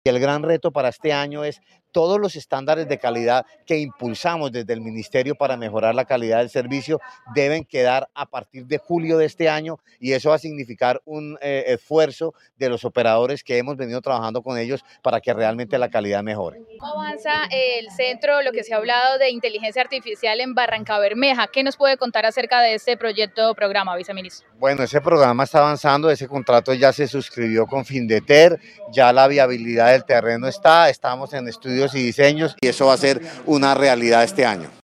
Gabriel Jurado, Viceministro de Conectividad